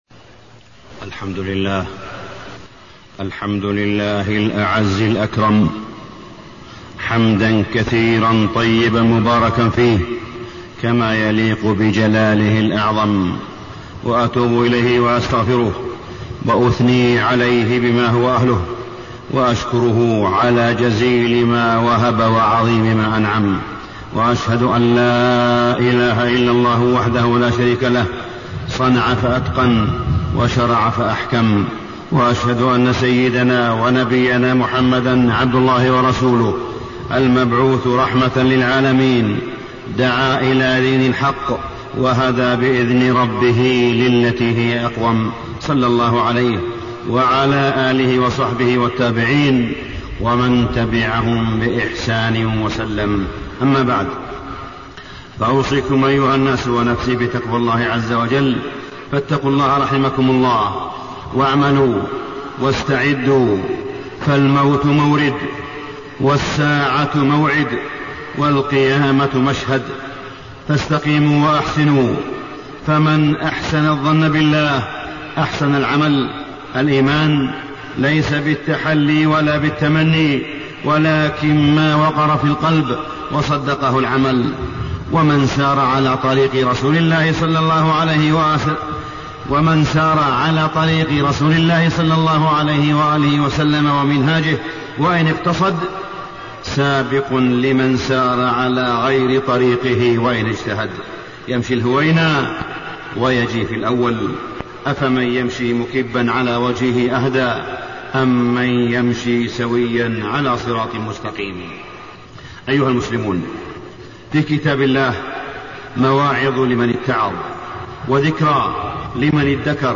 تاريخ النشر ٣ رمضان ١٤٣١ هـ المكان: المسجد الحرام الشيخ: معالي الشيخ أ.د. صالح بن عبدالله بن حميد معالي الشيخ أ.د. صالح بن عبدالله بن حميد الخوف والخشية